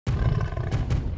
walk_1.wav